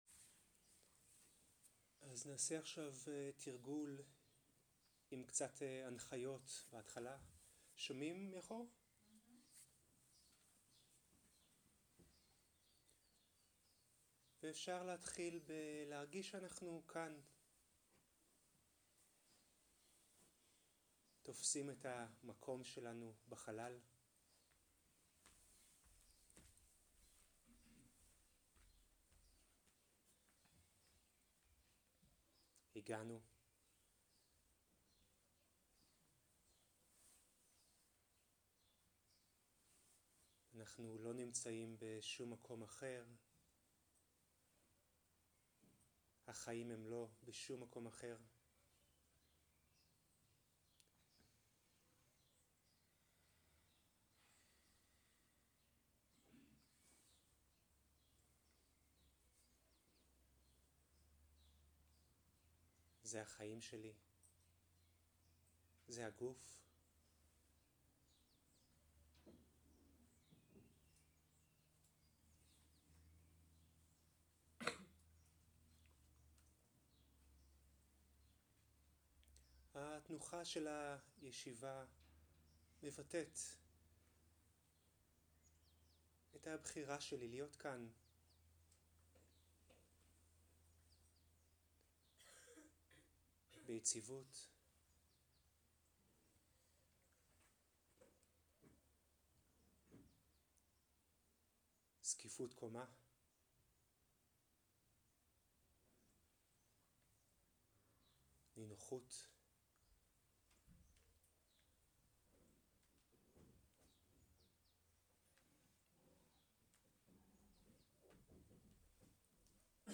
סוג ההקלטה: שיחת הנחיות למדיטציה
איכות ההקלטה: איכות גבוהה